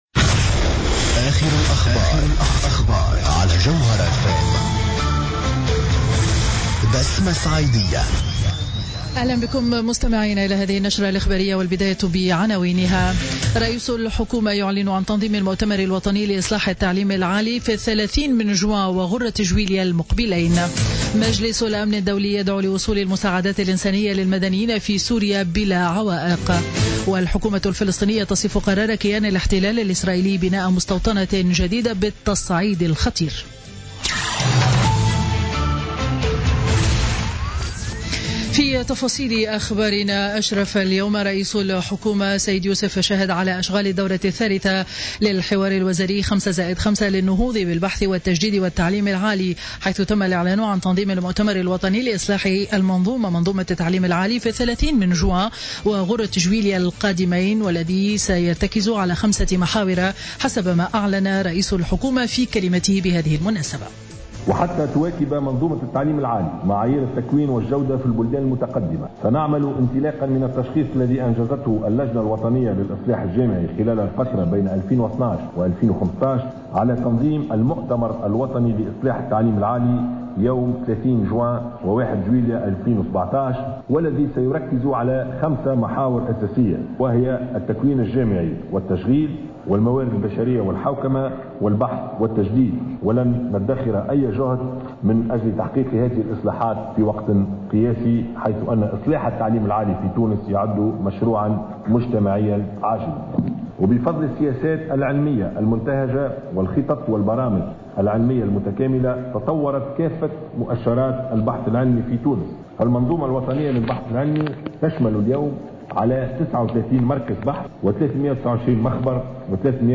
نشرة أخبار منتصف النهار ليوم الجمعة 31 مارس 2017